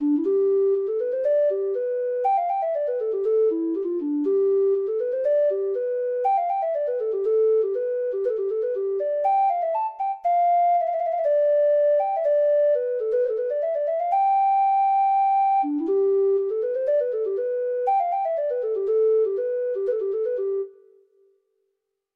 Traditional Sheet Music